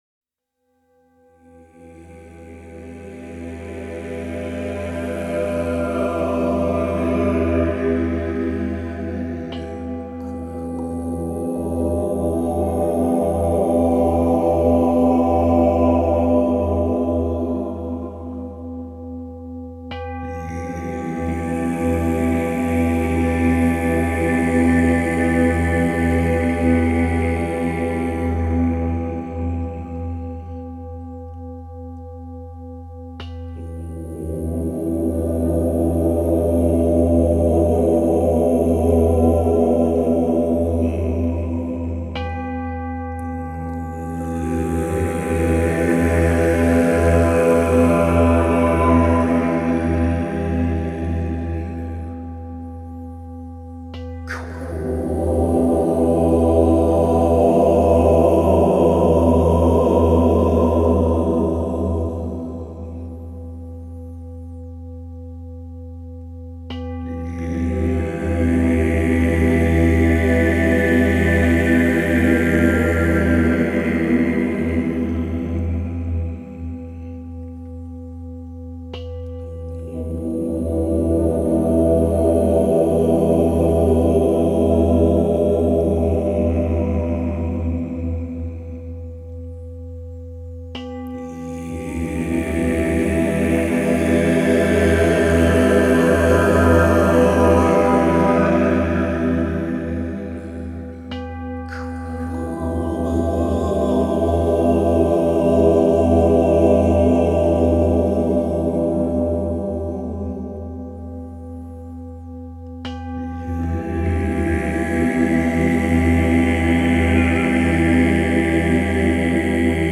Man könnte diese Musik auch als Klang-Chiffren bezeichnen.
Atemberaubend - eindringlich - magisch!